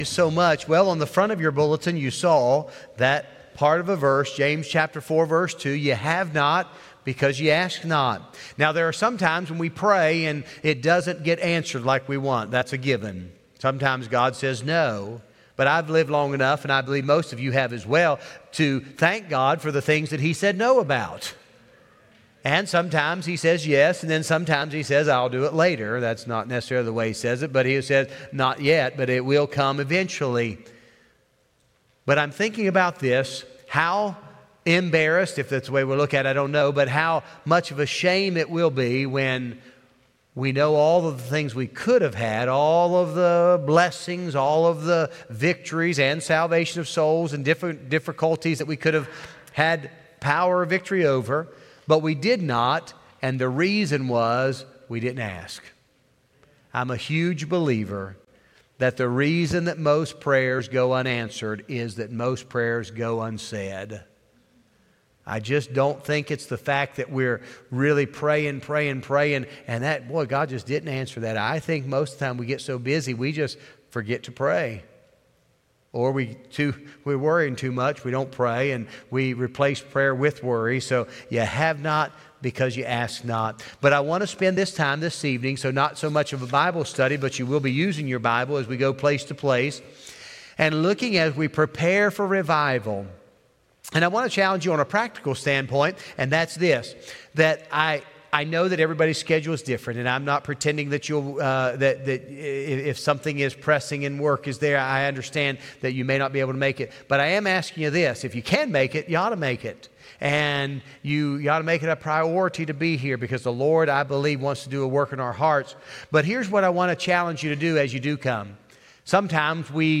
Wednesday Evening Service